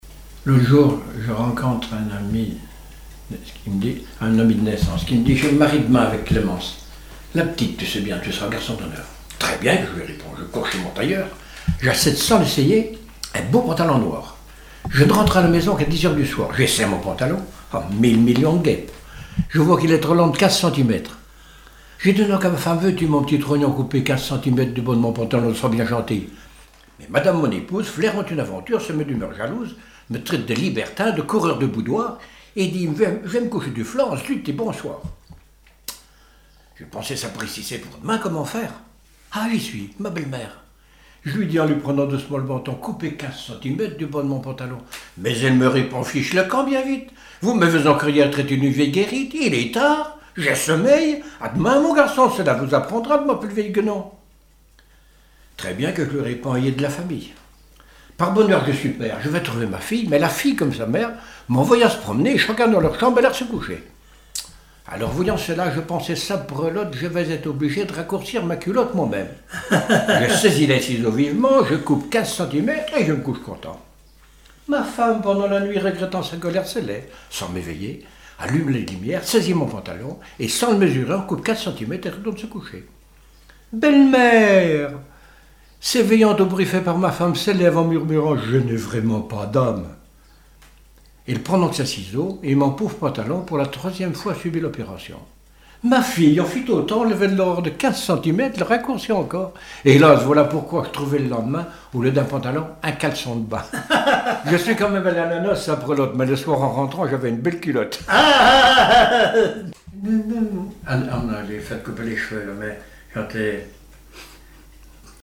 Genre sketch
Airs gavotés et histoires drôles
Catégorie Récit